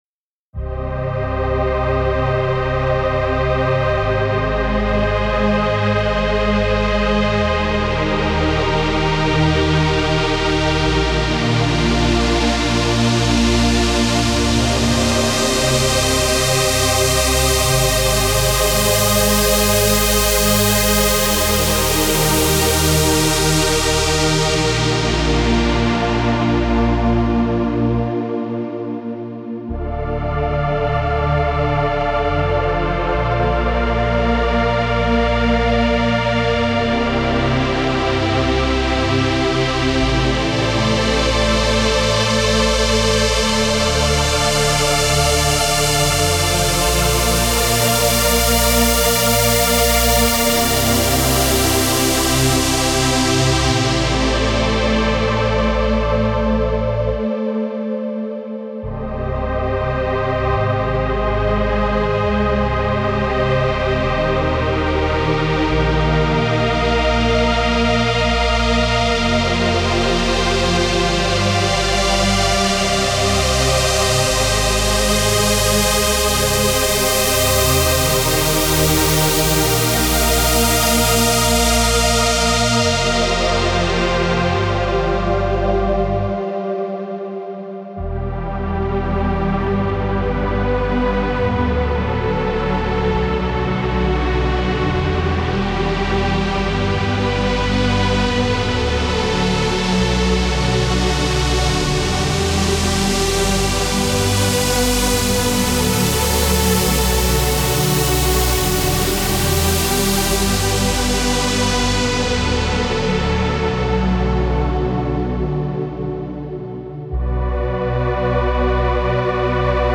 Trance Uplifting Trance
10 x Pad Melody
10 x Bass Line
(Preview demo is 140 BPM)
Style: Trance, Uplifting Trance